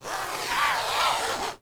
FABRIC_CLOTHING
ZIPPER_Long_03_mono.wav